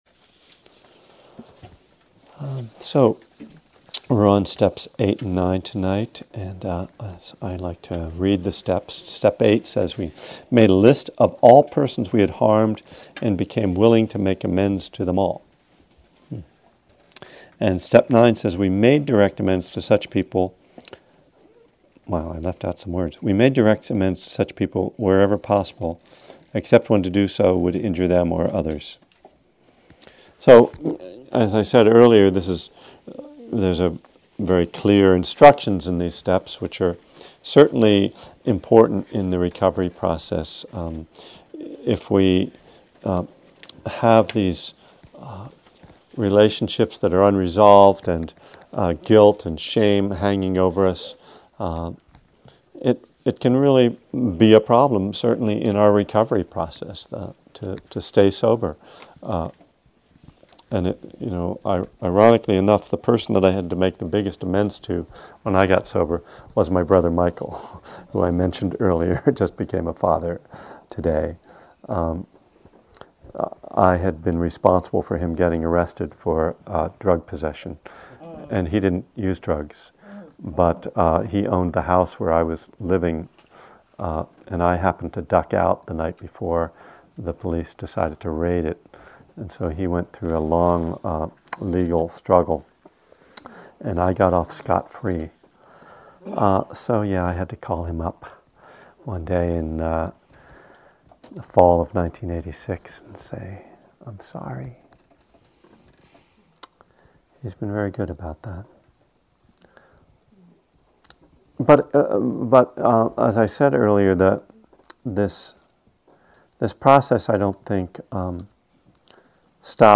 From the Buddhism and the Twelve Steps class series at Spirit Rock Meditation Center in March, 2011.